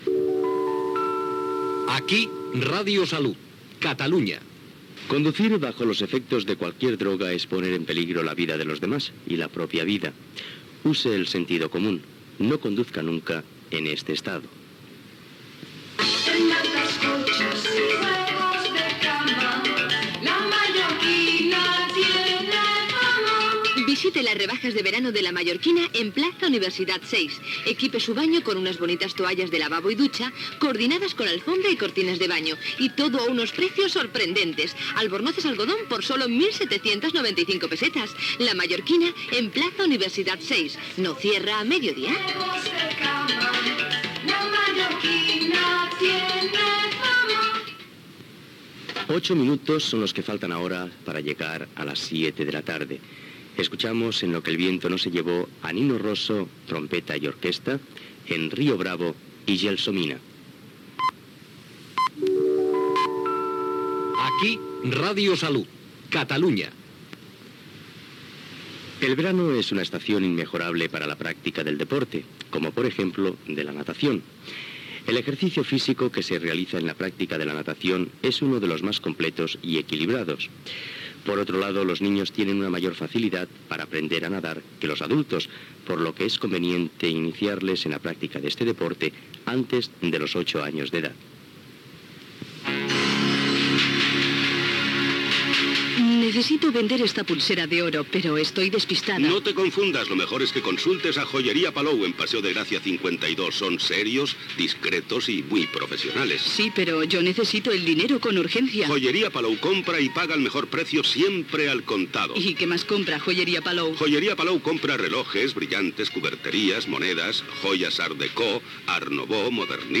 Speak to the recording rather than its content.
Indicatiu de l'emissora, si beus no condueixis, publicitat, hora, indicatiu, la natació és un esport complet, publicitat, hora, identificació del programa, temperatura, temes musicals FM